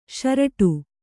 ♪ śaraṭu